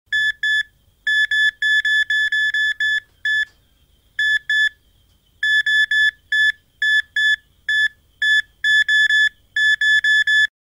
Звуки металлоискателя
На этой странице собраны звуки металлоискателей разных типов: от монотонных сигналов старых моделей до современных многозональных тонов.